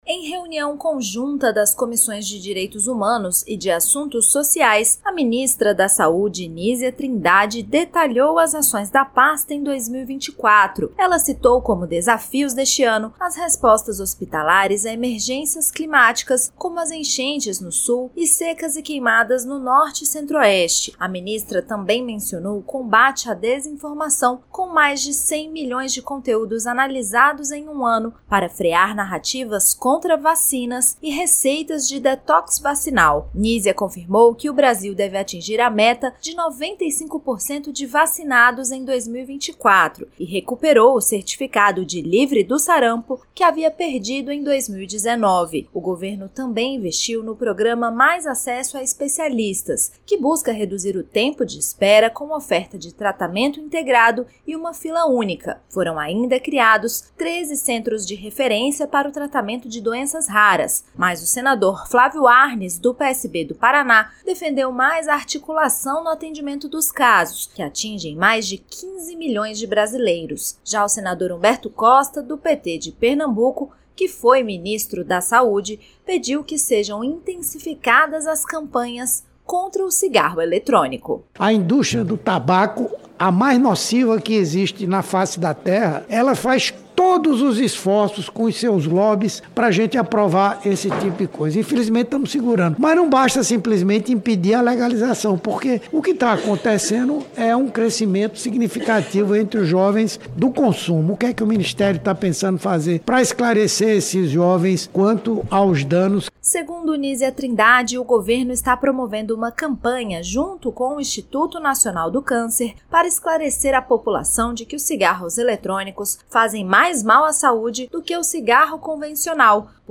A ministra da Saúde, Nísia Trindade, participou, nesta quarta-feira (11), de uma audiência pública conjunta das comissões de Direitos Humanos (CDH) e de Assuntos Sociais (CAS).